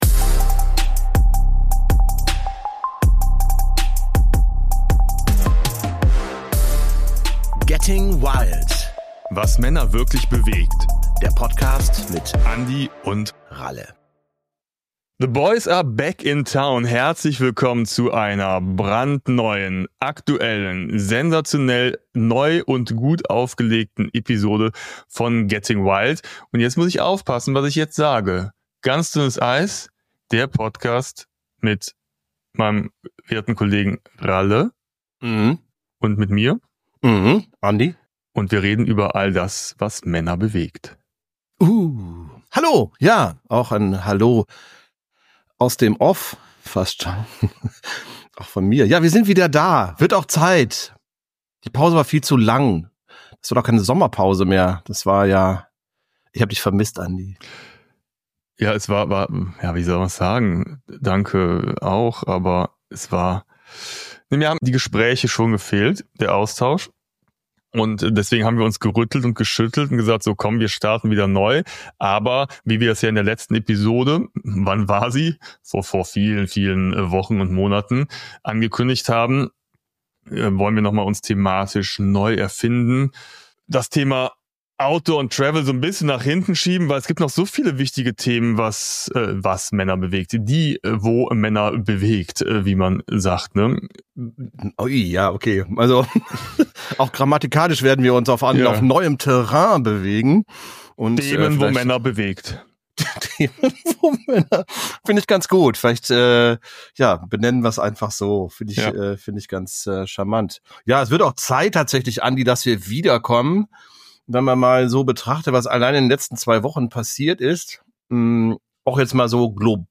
Zukünftig wird dem bewährt lockeren Geplausche über Reisen durchaus mehr gesellschaftliche Relevanz beigemixt.